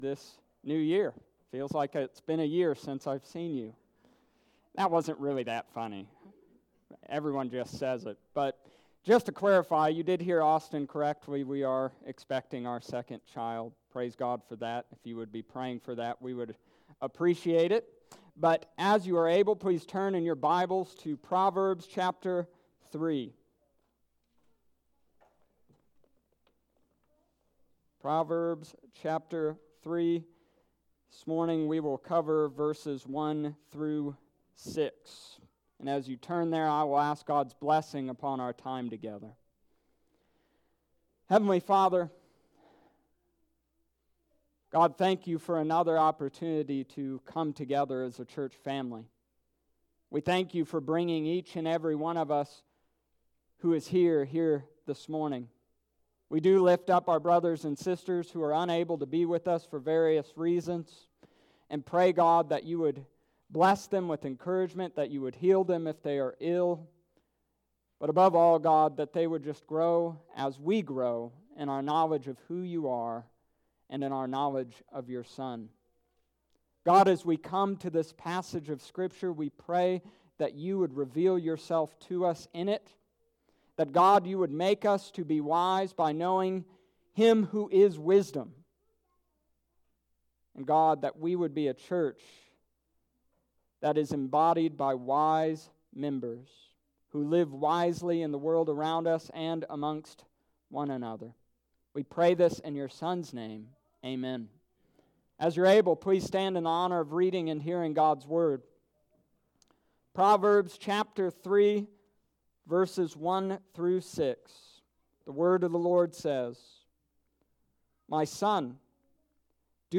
Summary of Sermon: This week we discussed living wisely.